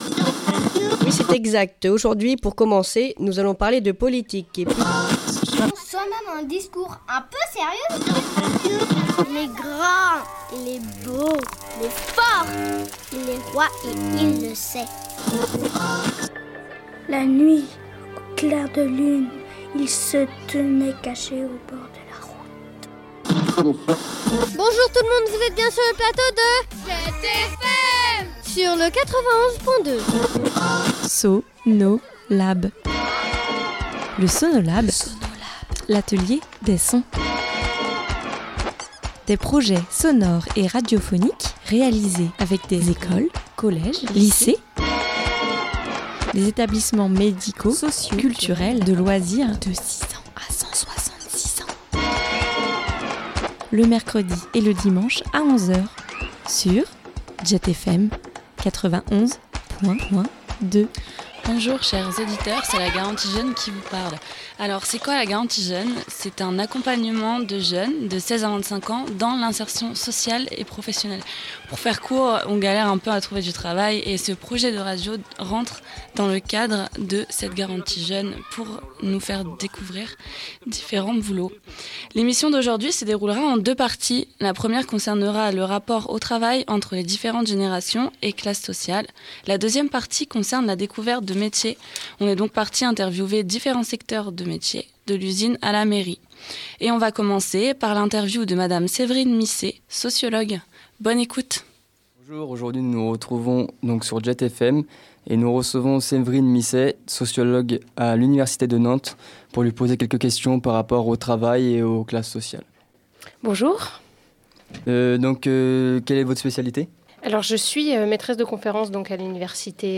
Une émission sur le travail concoctée par douze jeunes curieux.
Chacune des promotions travaille sur un projet collectif et pour ce groupe, vous l’aurez deviné, c’est un atelier radio, Radio GJ en l’occurrence.